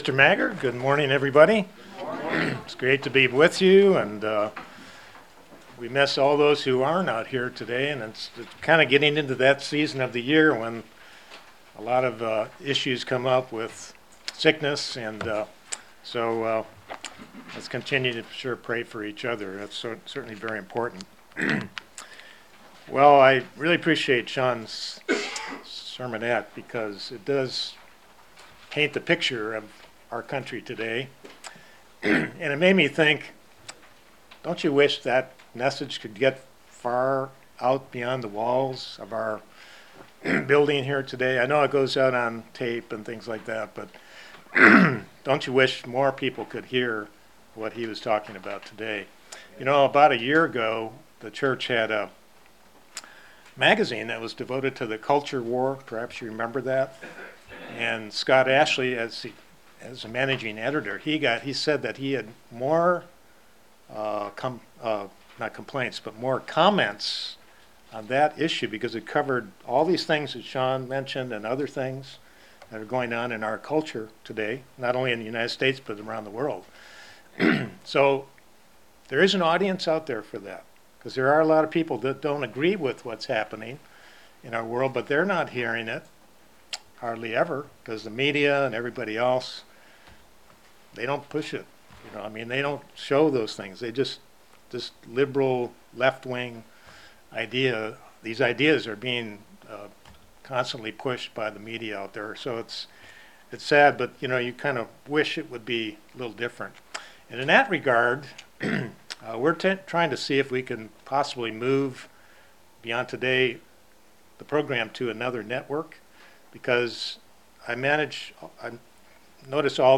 Sermons Reviving The Heart